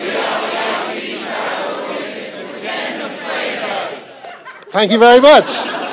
audience say hey